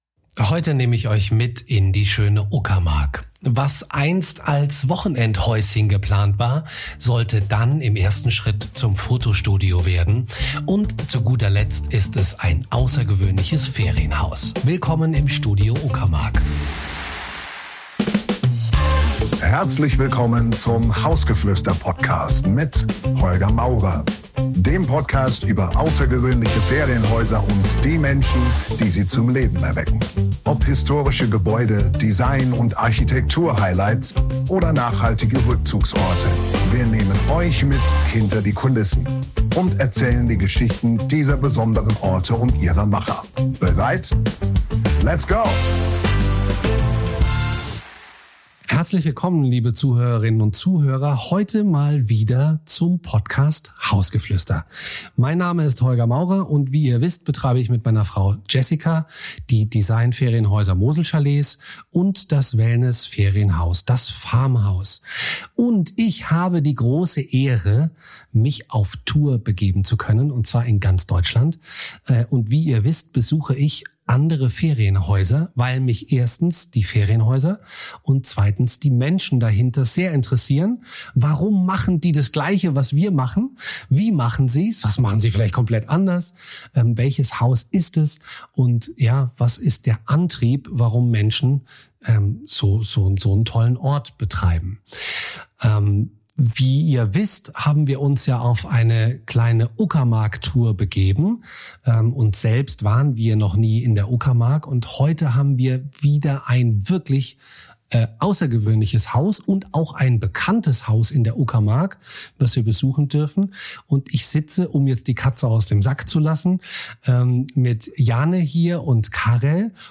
Ein Gespräch über Mut, Ästhetik, Architektur und die Kraft eines Ortes, der so besonders ist, dass Gäste ihn nur schwer wieder verlassen können.